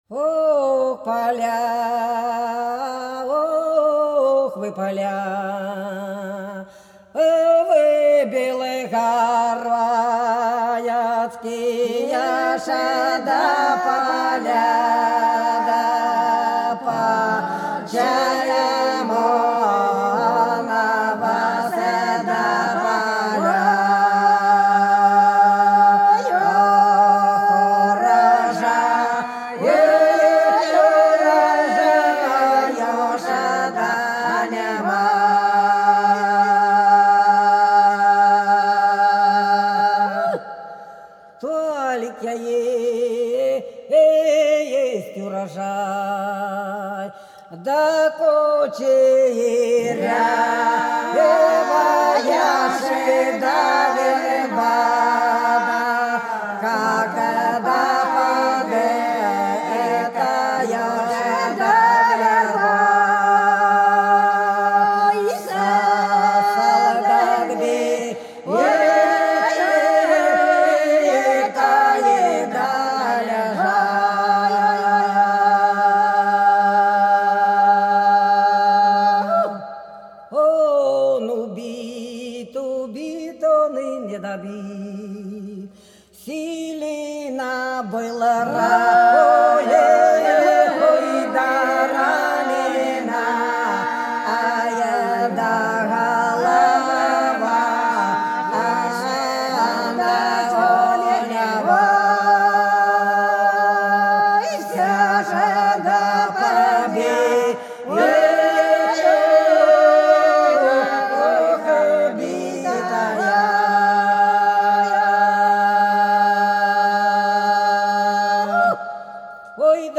Белгородские поля (Поют народные исполнители села Прудки Красногвардейского района Белгородской области) Ох, поля, вы, поля, Белгородские поля - протяжная